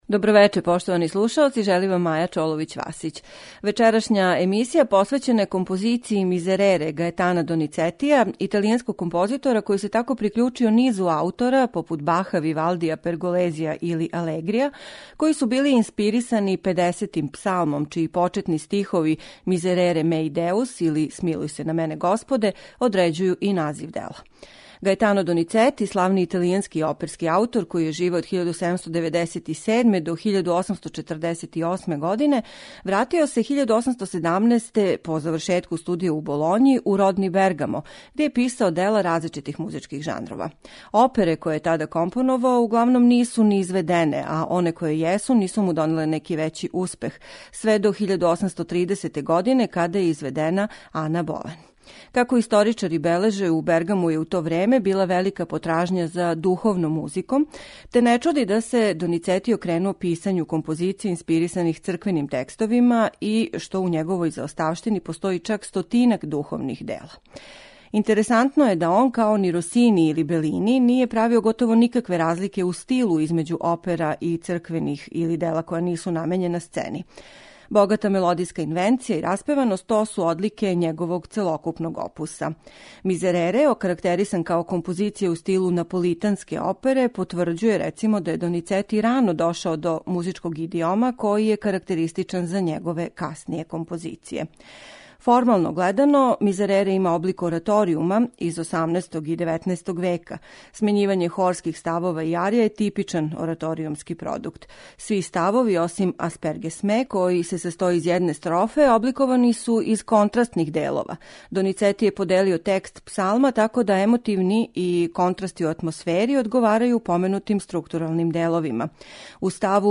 духовну композицију
медитативне и духовне композиције